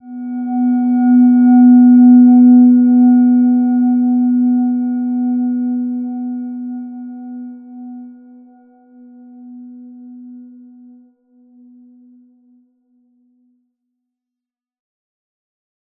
Silver-Gem-B3-f.wav